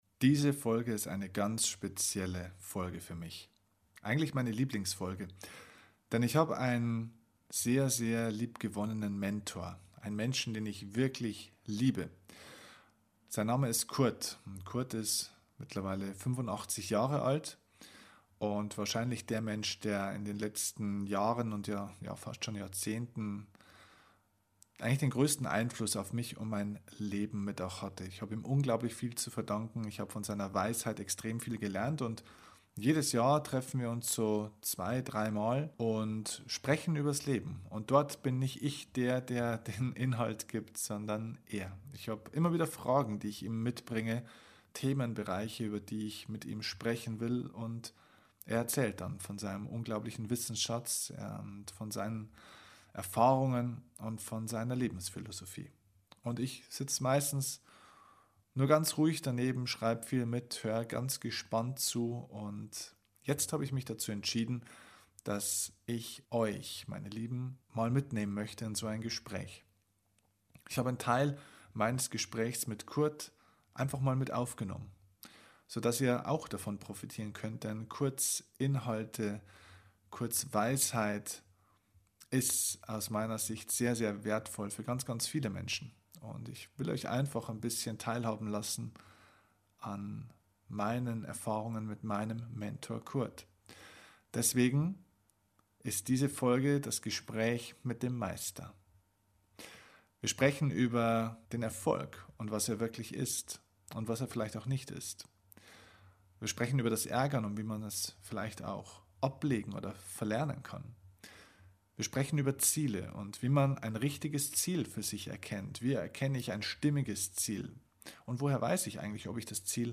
Ich habe für Dich nun einen Teil unseres Gesprächs mit aufgezeichnet, um Dich an seinen Sichtweisen und seiner Weisheit teilhaben zu lassen.